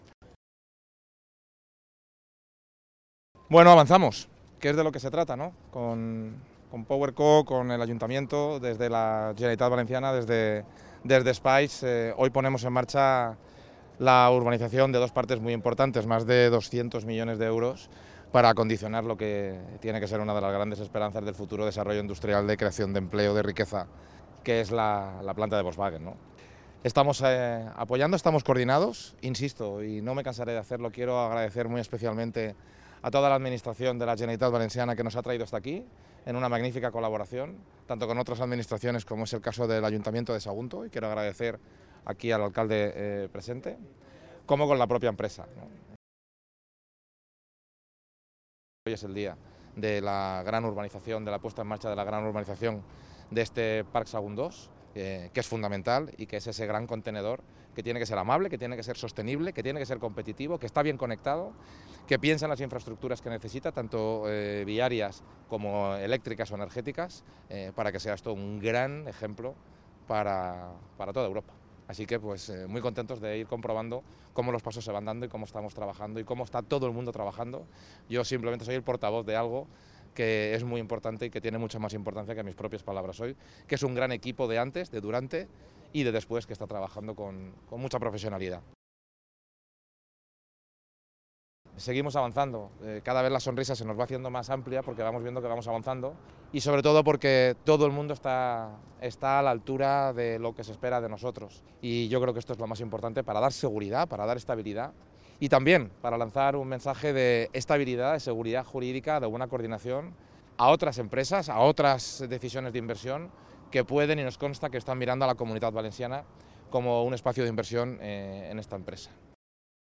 Así lo ha manifestado tras visitar la parcela donde PowerCo construirá su factoría de baterías eléctricas, así como la Alquería de l’Advocat, donde se ubicará la nueva sede de Espais Econòmics Empresarials, entidad que gestiona Parc Sagunt.